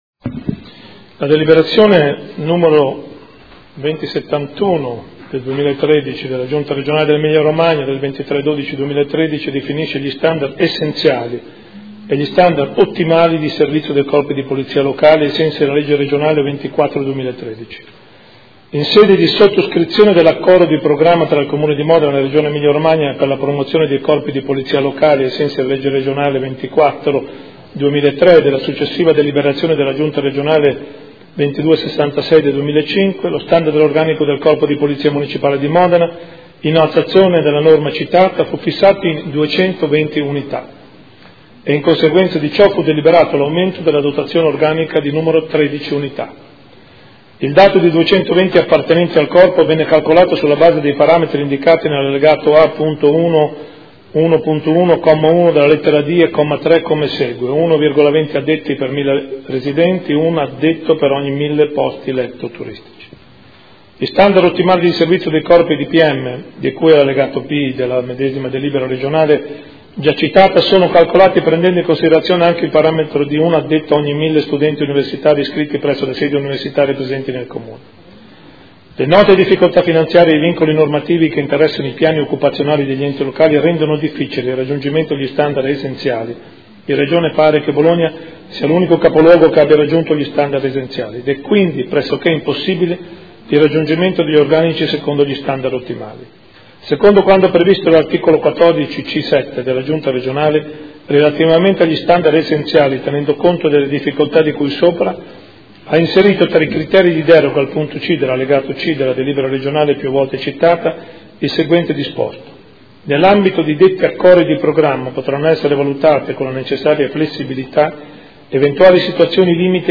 Gian Carlo Muzzarelli — Sito Audio Consiglio Comunale